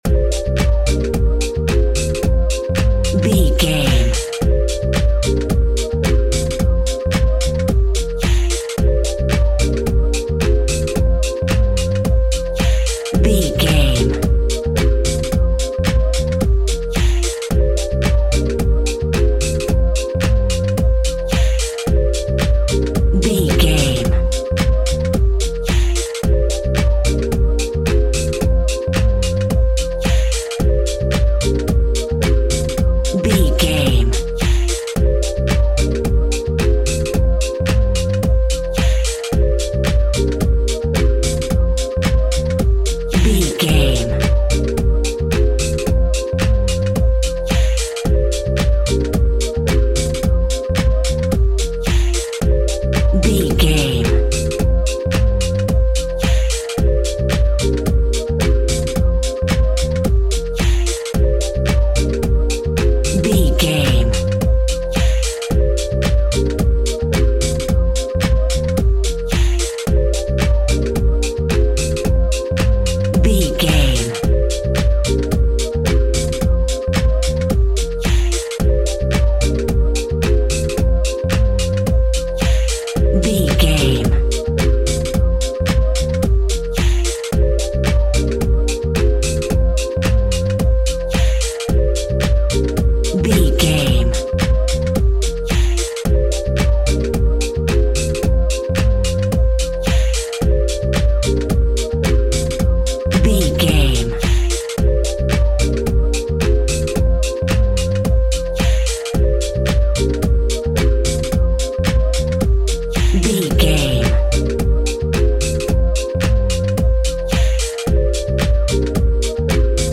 Ionian/Major
B♭
peaceful
tranquil
meditative
smooth
drum machine
synthesiser
Lounge
chill out
laid back
nu jazz
downtempo
synth leads
synth bass